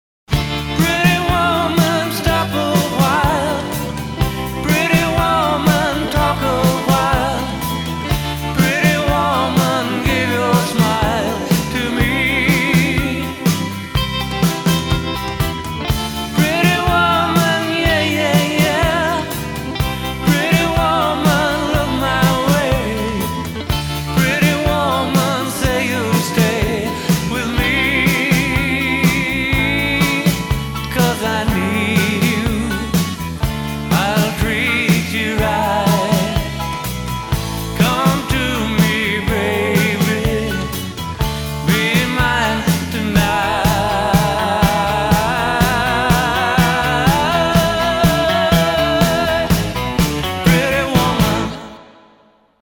• Качество: 320, Stereo
OST